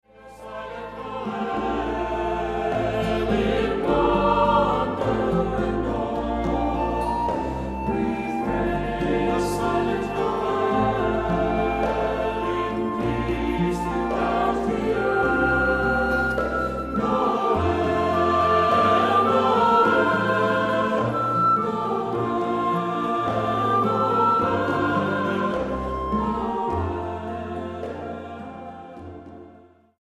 Accompaniment:      Piano, Flute;Violin
Music Category:      Choral